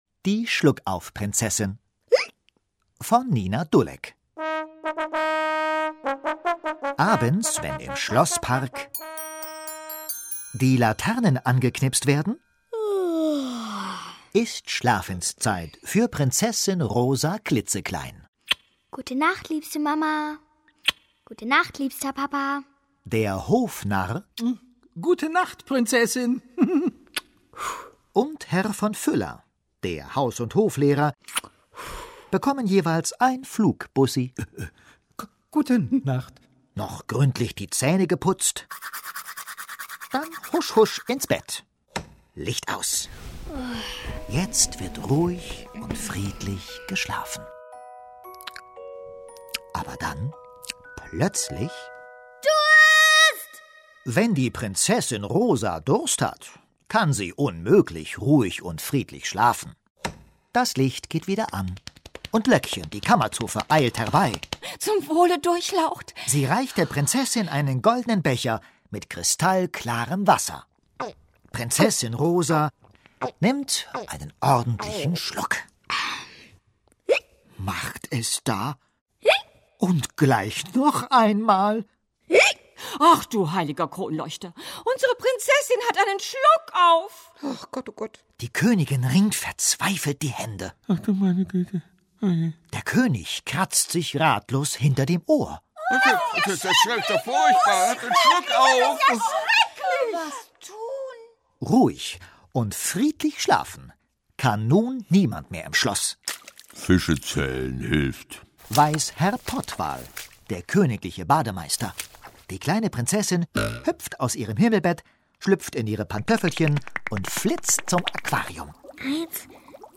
Szenische Lesungen mit Musik (1 CD)